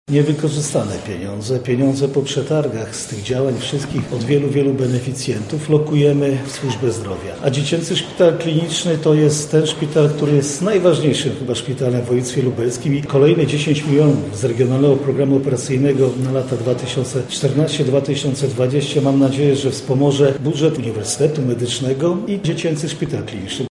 • mówi Jarosław Stawiarski, marszałek województwa lubelskiego.